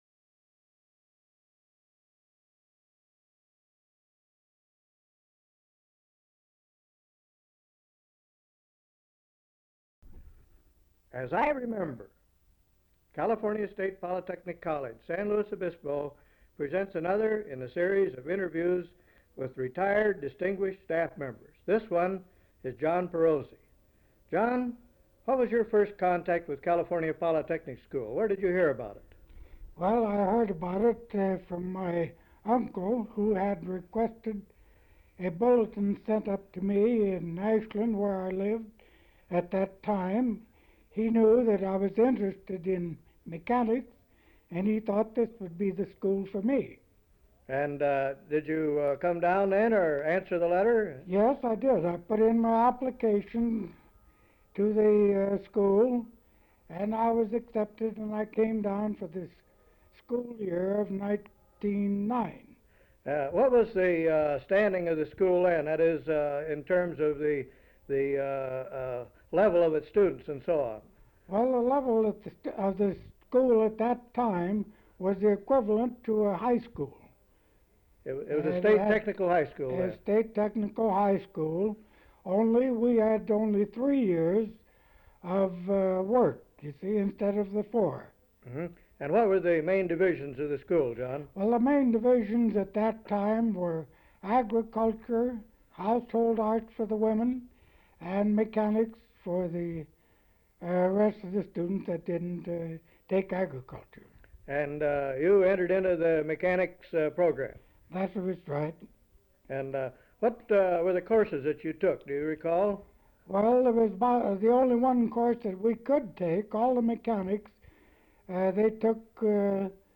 Interview
Form of original Open reel audiotape